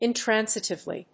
音節in･tran･si･tive･ly発音記号・読み方ɪntrǽnsətɪvli